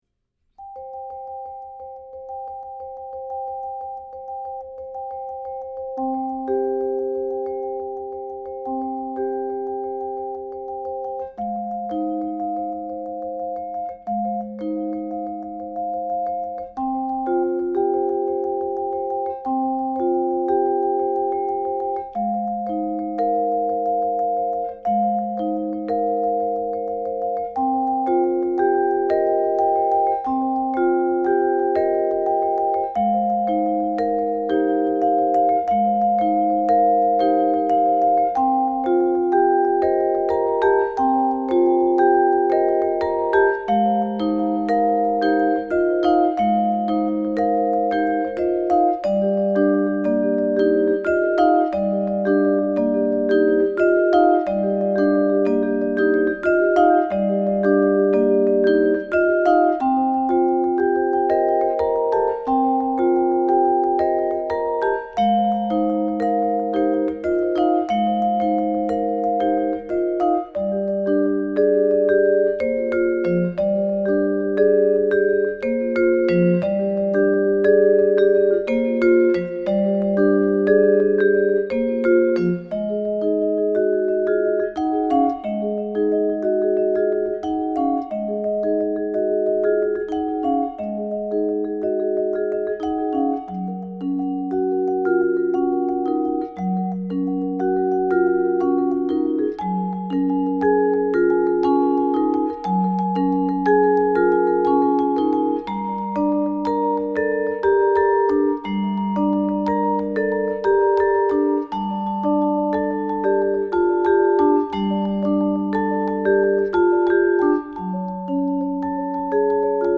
Mallets
Vibraphon Solo
Minimalmusic für Solo Vibraphon Schwer, 6:40 min.